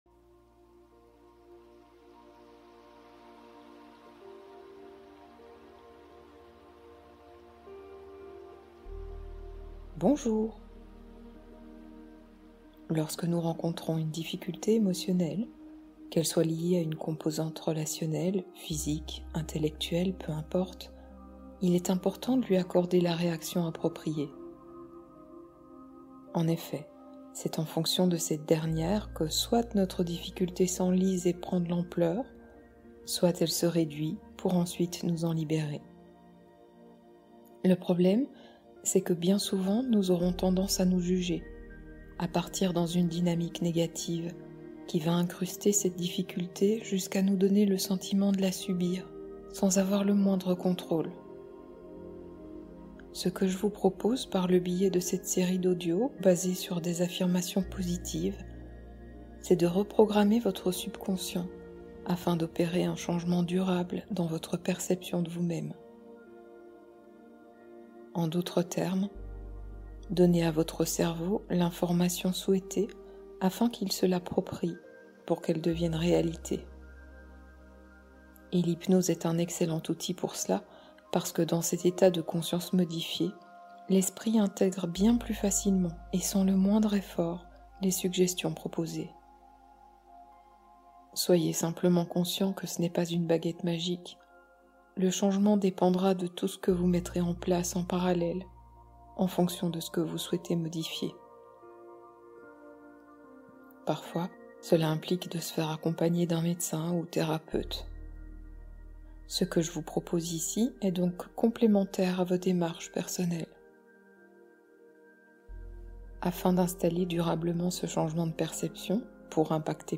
Lâcher le contrôle : hypnose de libération